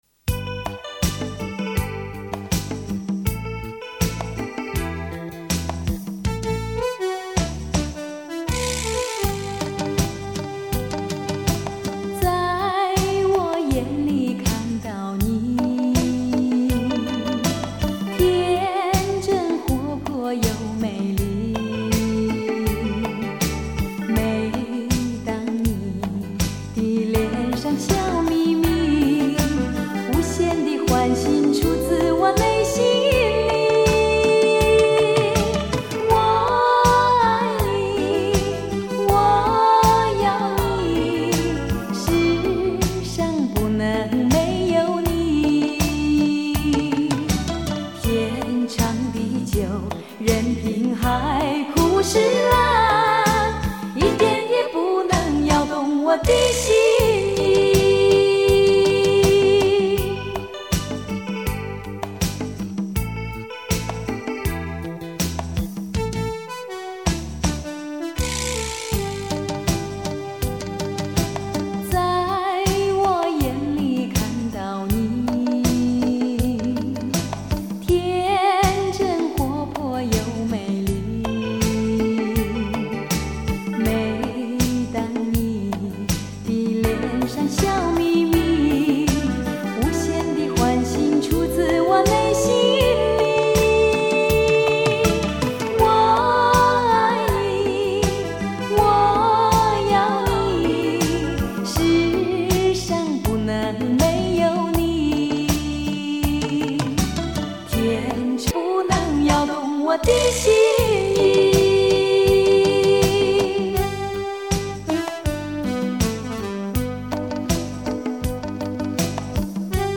一组曾经风靡于台湾各色小酒吧的情歌恋曲